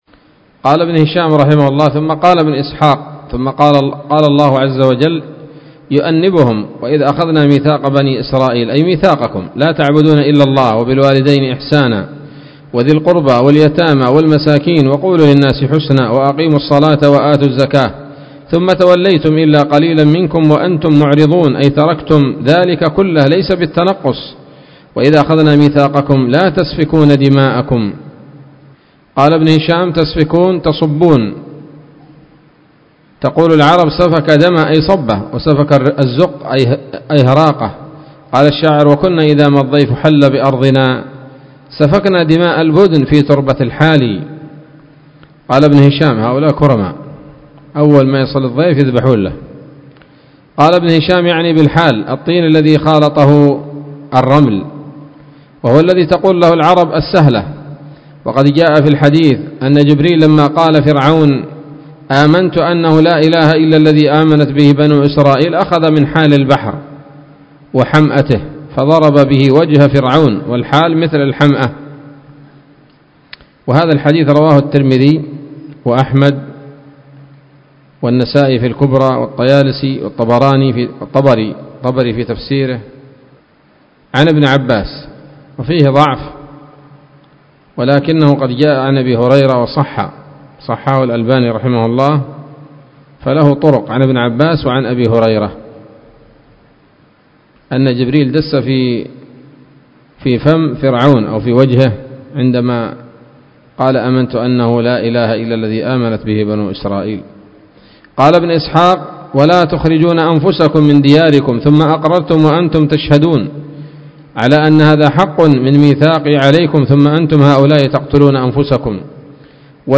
الدرس التسعون من التعليق على كتاب السيرة النبوية لابن هشام